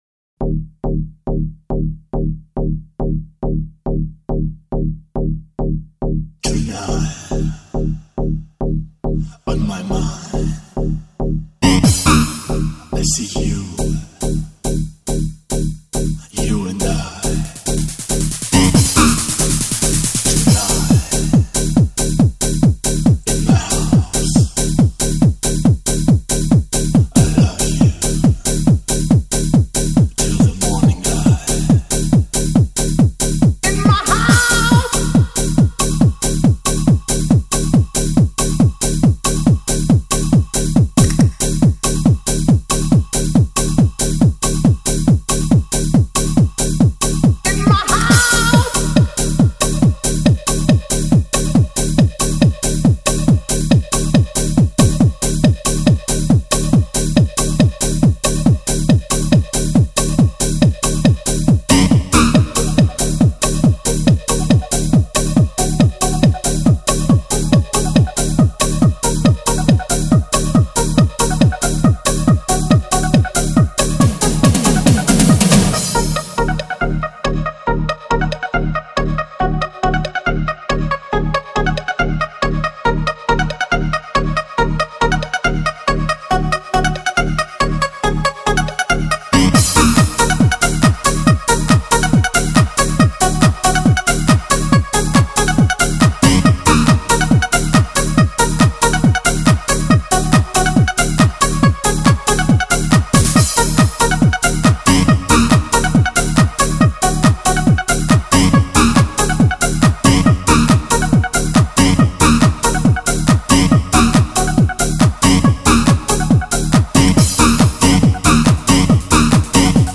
Жанр: Club-House